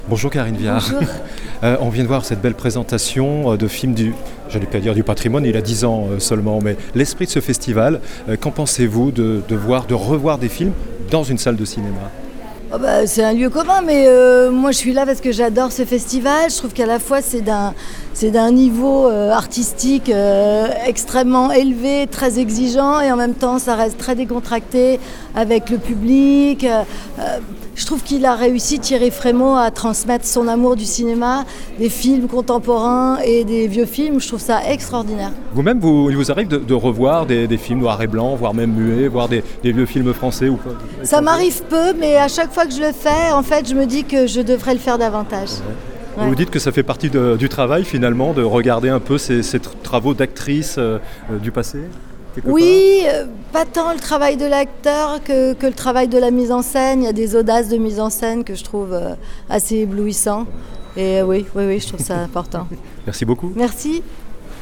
L'actrice Karin Viard était invitée au Festival Lumière de Lyon 2023. "Je suis une grande fan du Festival Lumière" "Je trouve qu'il est d'un niveau artistique extrêmement élevé, très exigeant et en même temps très décontracté avec le public.